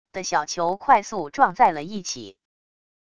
的小球快速撞在了一起wav音频